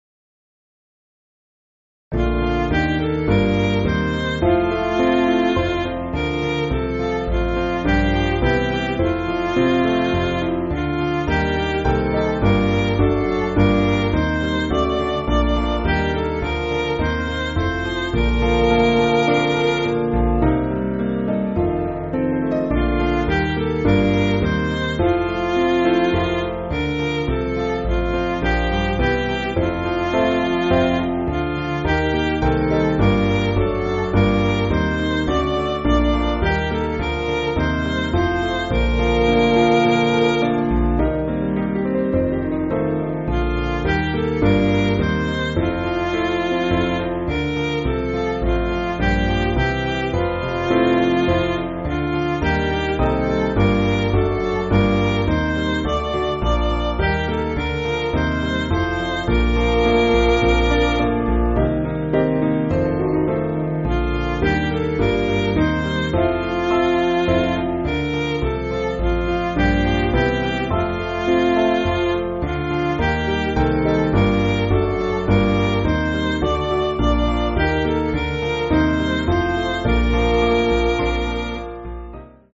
Piano & Instrumental
(CM)   5/Bb